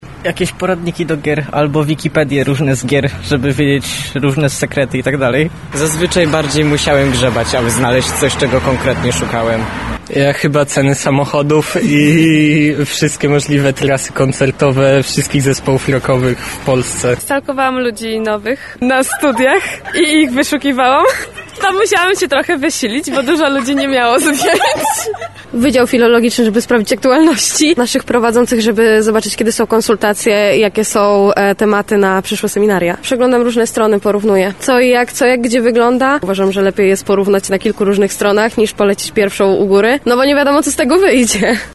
[Sonda] Google podsumowuje rok – co najchętniej wyszukiwali użytkownicy?
Zapytaliśmy także mieszkańców Lublina o to, co najczęściej wyszukiwali w popularnej przeglądarce: